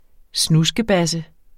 Udtale [ ˈsnusgəˌbasə ]